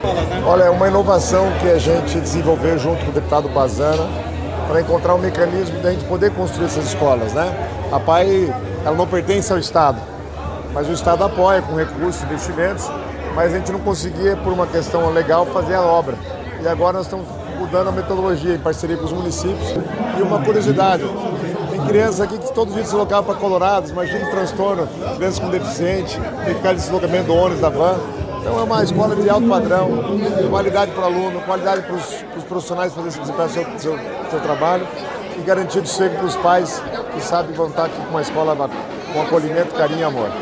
Sonora do secretário das Cidades, Guto Silva, sobre a inauguração da nova Apae de Nossa Senhora das Graças